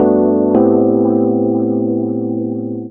08 Dr Kildare 165 G.wav